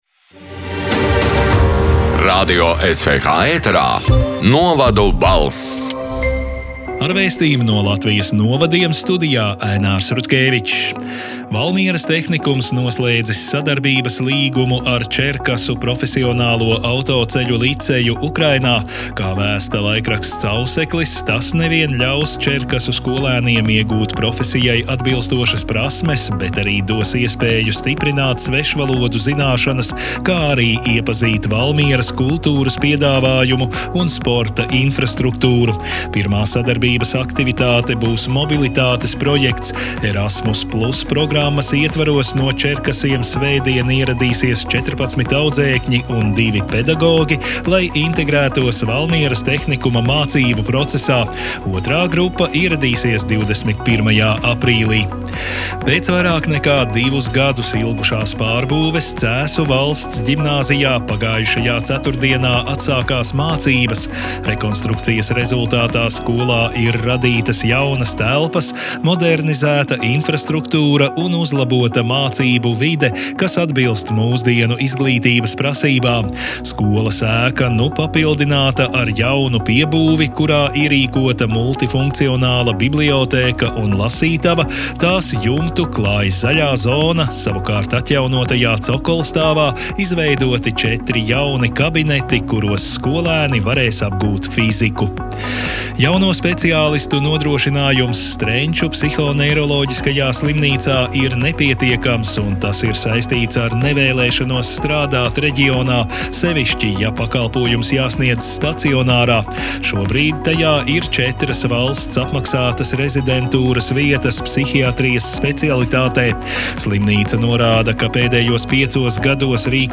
Radio SWH ēterā divreiz nedēļā izskan ziņu raidījums “Novadu balss”, kurā iekļautas Latvijas reģionālo mediju sagatavotās ziņas. Raidījumā Radio SWH ziņu dienests apkopo aktuālāko no laikrakstiem “Auseklis”, “Kurzemes Vārds”, “Zemgales Ziņas” un ”Vietējā Latgales Avīze”.
“Novadu balss” 26. marta ziņu raidījuma ieraksts: